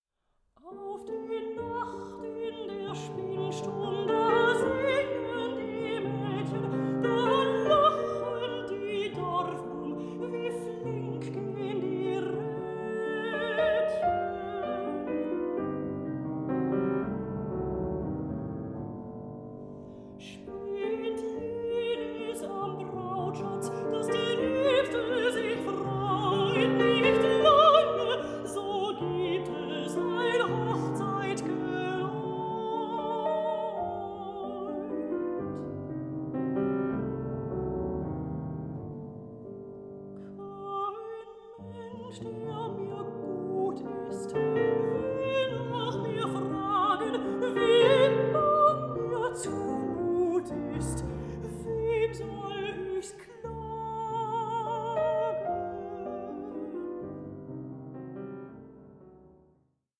44.1/16 KHZ stereo  10,99 Select
mezzo-soprano
pianist